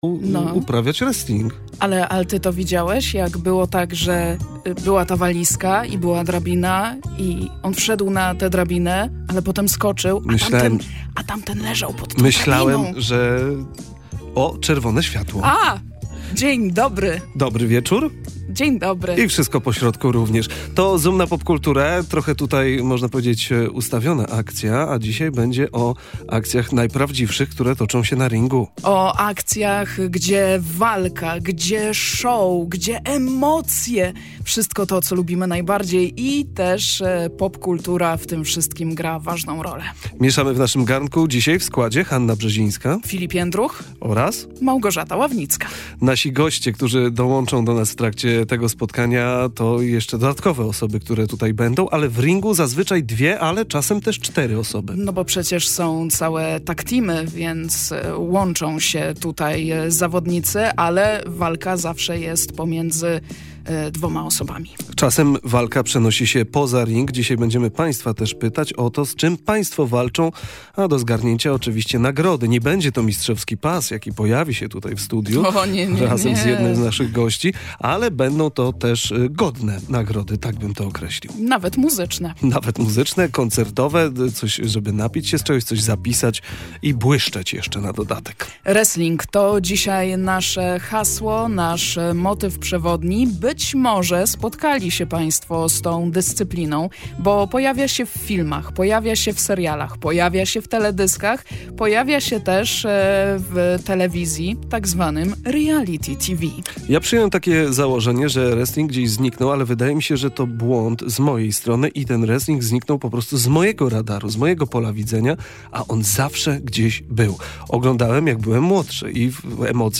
Rozmowa o pasji, bohaterach, ringu i kulisach